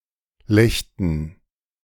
Legden (German pronunciation: [ˈlɛçdn̩]
De-Legden.ogg.mp3